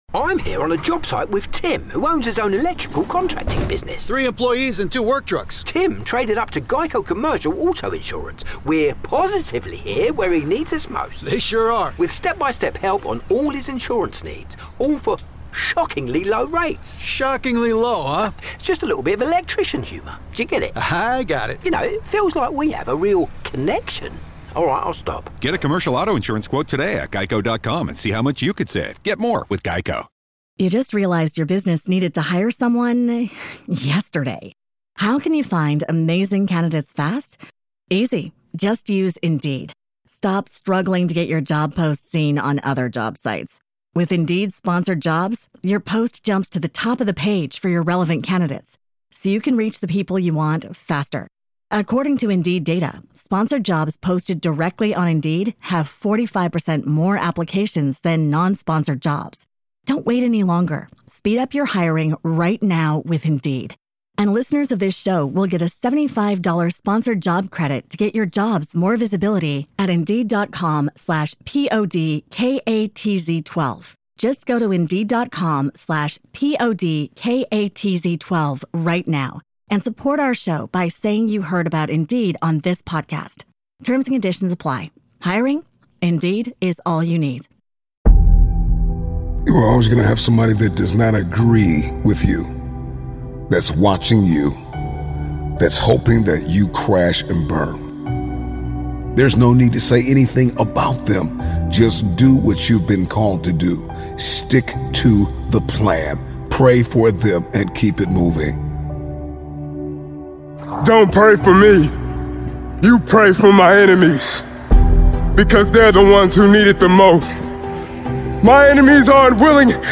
One of the Best Motivational Speeches of 2024